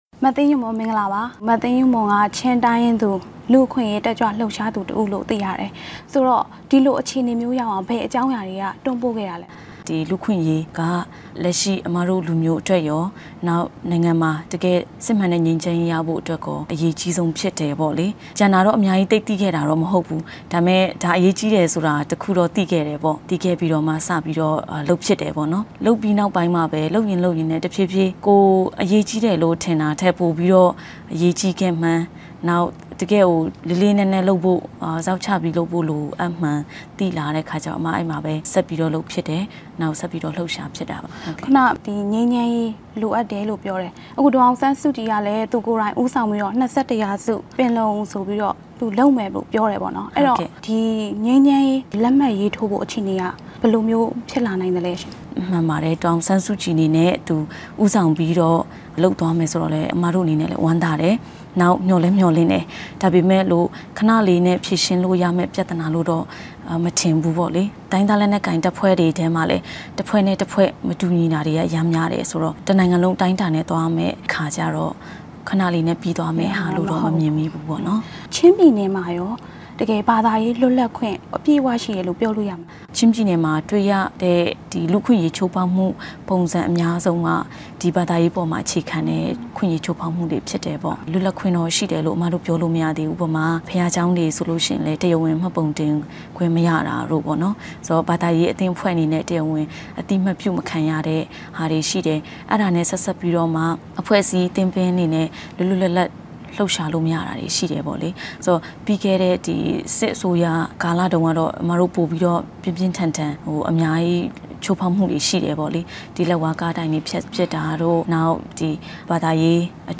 တွေ့ဆုံမေးမြန်းချက်